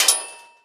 assemblerStrike1.ogg